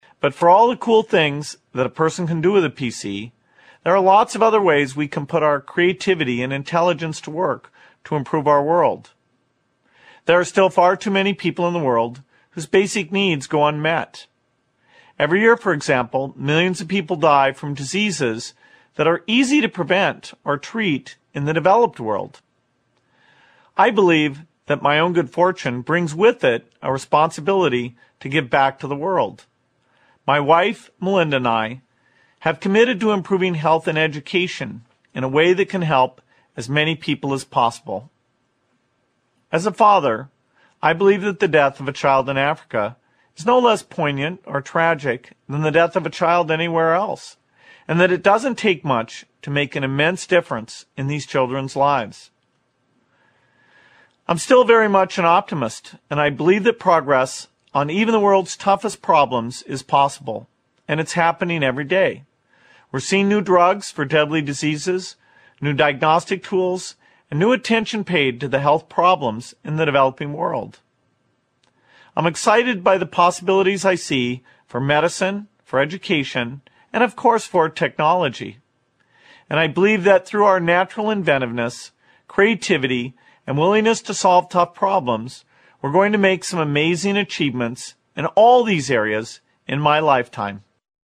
名人励志英语演讲 第99期:释放你的创造力(2) 听力文件下载—在线英语听力室